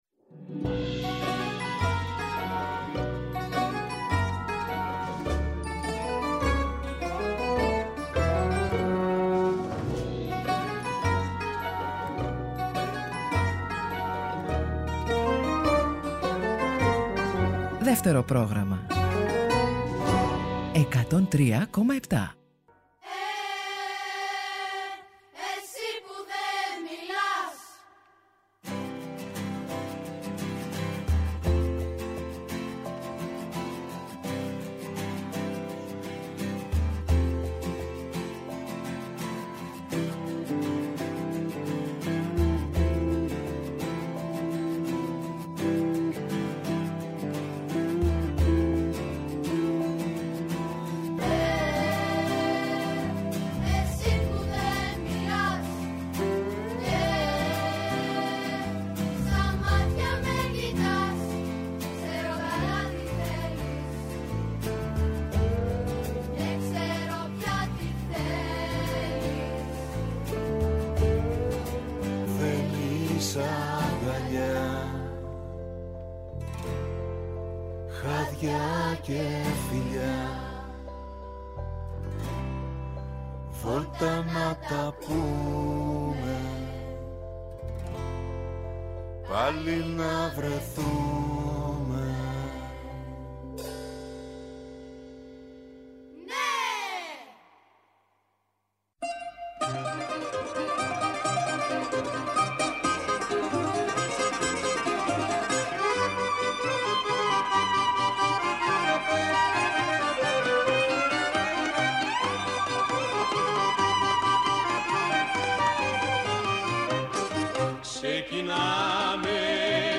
Πρόγραμμα εορταστικόν και βαθύτατα λαΐκό με τραγούδια αττάκτως ερριμένα,τραγούδια που έχουμε αγαπήσει και τραγουδήσει, δηλαδή είναι πια δικά μας…
Αυτό ήταν το ταρατατζούμ ρεπερτόριο του “Εσύ που δεν μιλάς”.